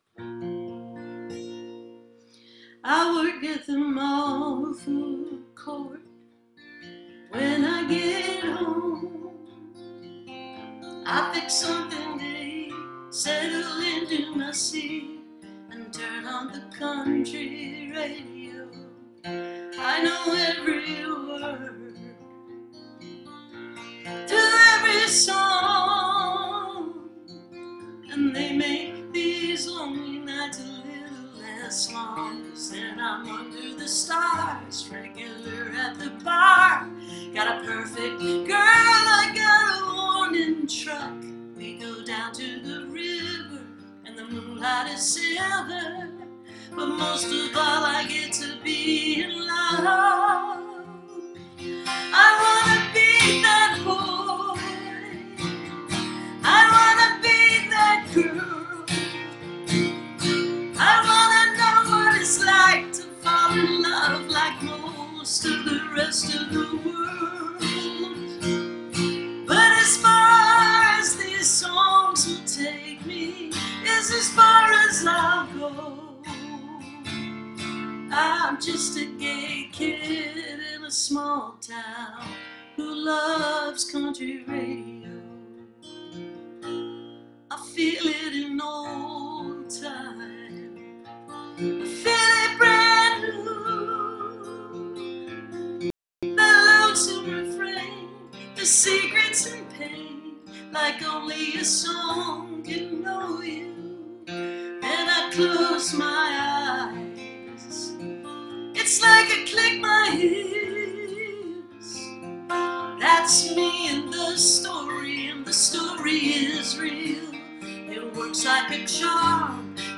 (captured from the live video stream)